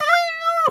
pgs/Assets/Audio/Animal_Impersonations/cat_2_meow_10.wav at master
cat_2_meow_10.wav